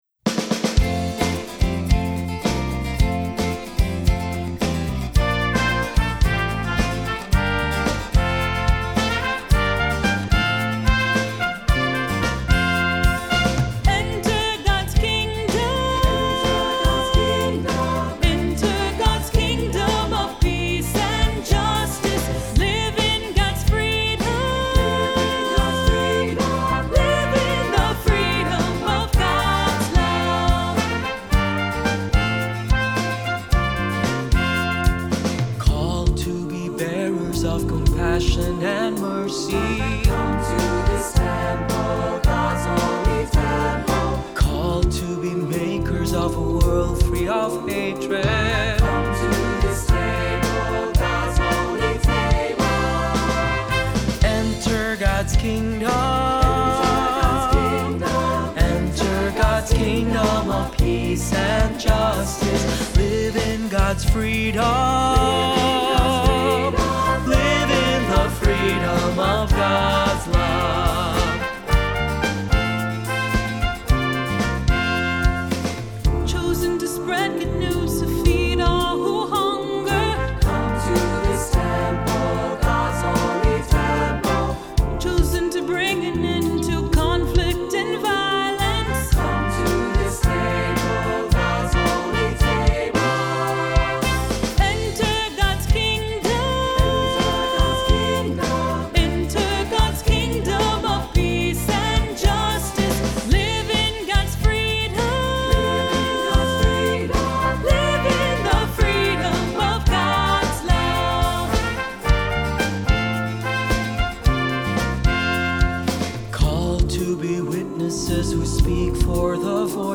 Accompaniment:      Keyboard
Music Category:      Christian
This lively piece
For cantor or soloist.